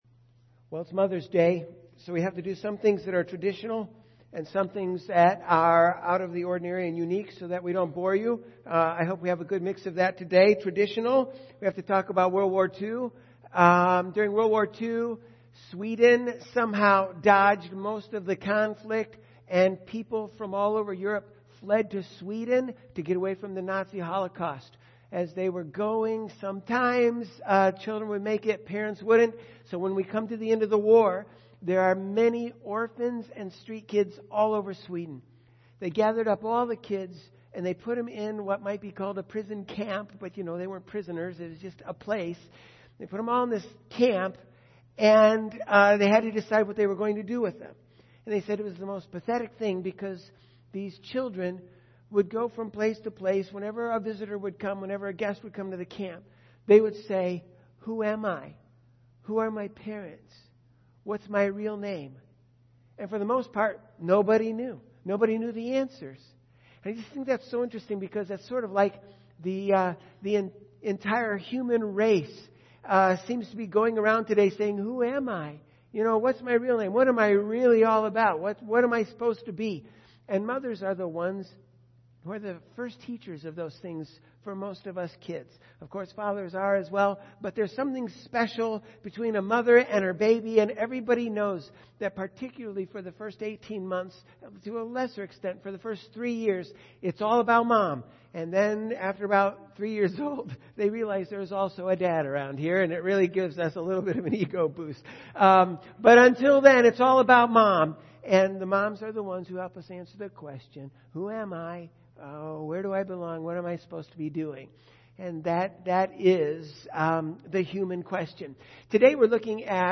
Mother’s Day Service